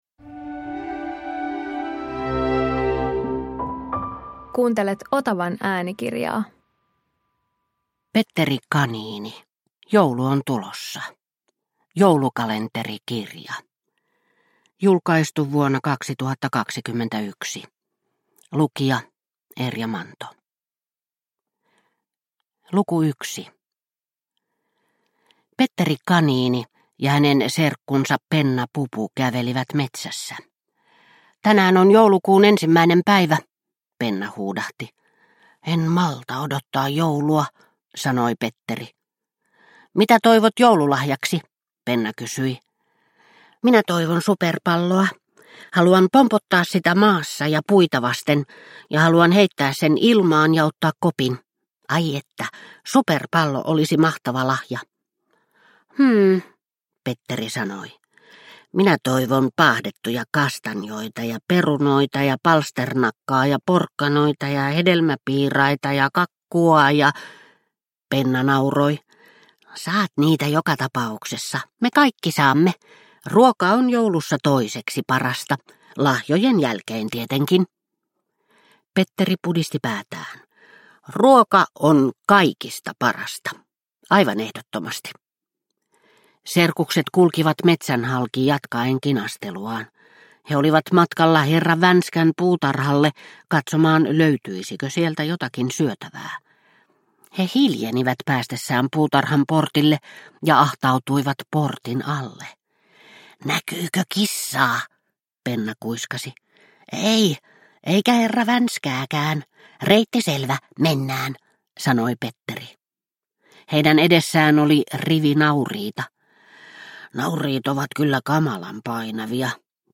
Petteri Kaniini - joulu on tulossa. Joulukalenterikirja – Ljudbok – Laddas ner